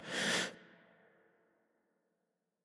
Add coughing and breaths
inhale.mp3